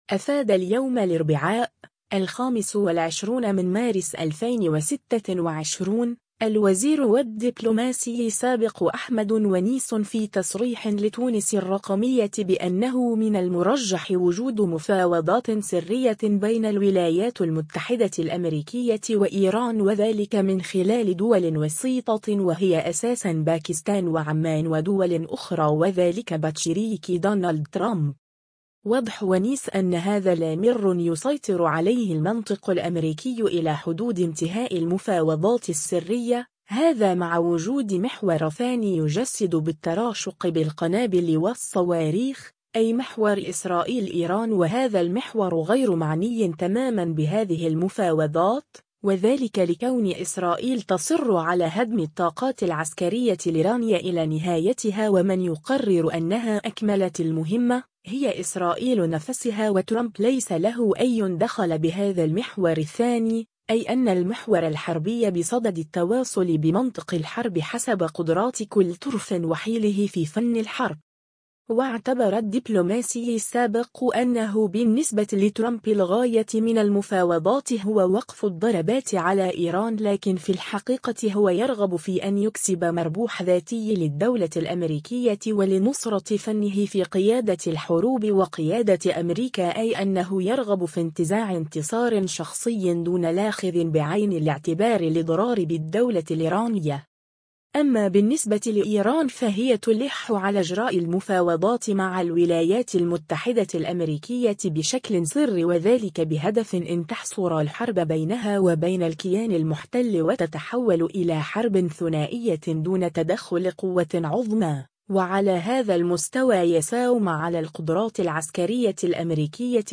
أفاد اليوم الاربعاء، 25 مارس 2026، الوزير و الدّبلوماسي السابق أحمد ونيس في تصريح لتونس الرّقمية بأنّه من المرجّح وجود مفاوضات سرّية بين الولايات المتحدة الأمريكية و إيران و ذلك من خلال دول وسيطة و هي أساسا باكستان و عمان و دول أخرى و ذلك بتشريك دونالد ترامب.